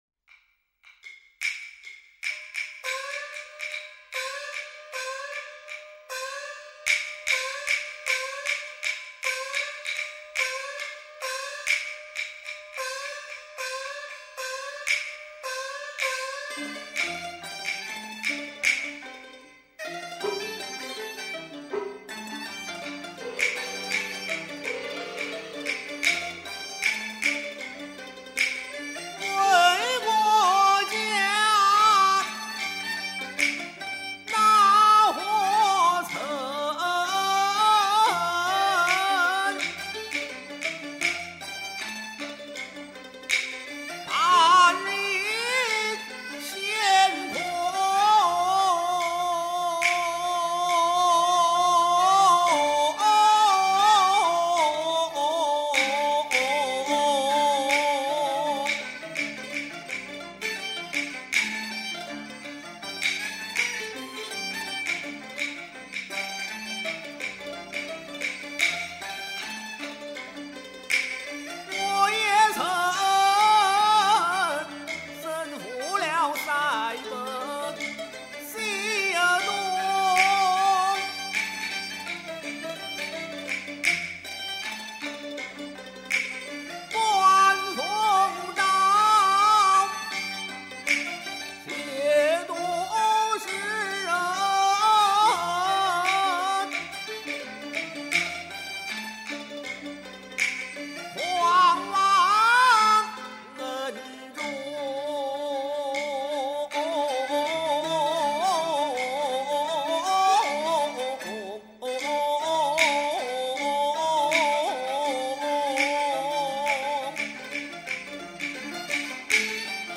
“為國家那何曾半日閑空”這段唱，是《洪洋洞》第二場裏，楊延昭出場時，感慨自己身體每況愈下，精力不支時的唱段。簡短的四句戲，采用傳統的二黃原板，唱腔瀟灑飄逸，一聲三歎。